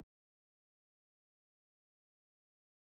Button.mp3